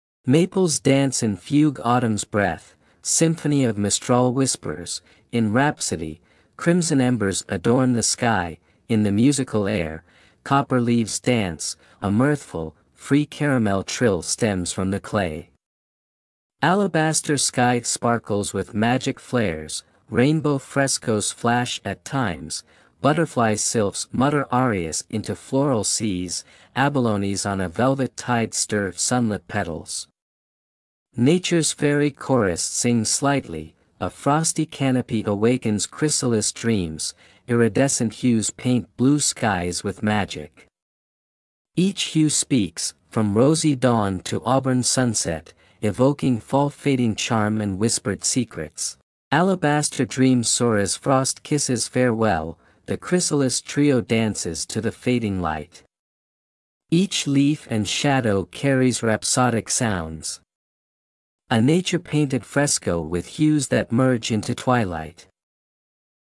The music and mood fit perfectly.